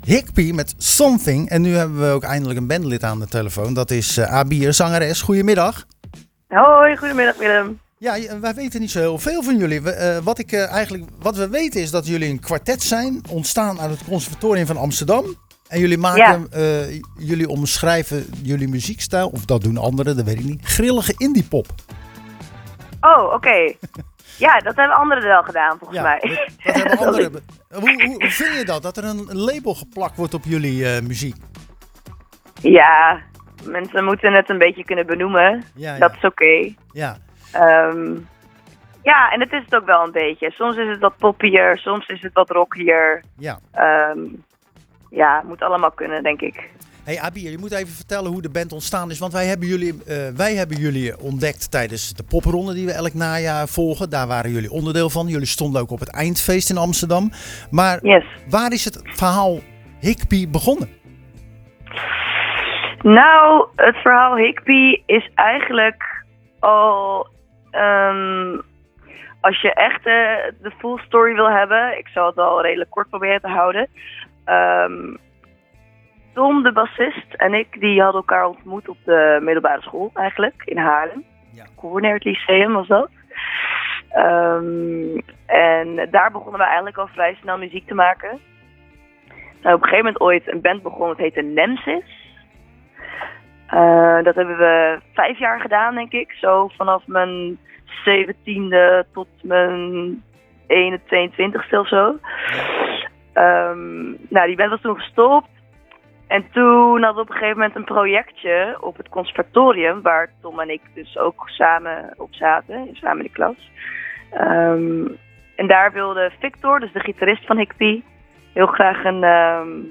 zangeres en gitariste